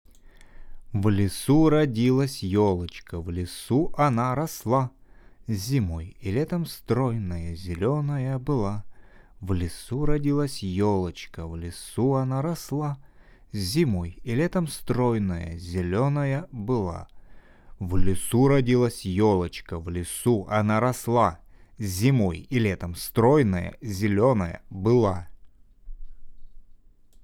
выбор микрофона: для студийной записи
Взяли на тест два конденсаторных микрофона. Запись велась, в обычной жилой квартире, в не подготовленной комнате 12 квадратных метров.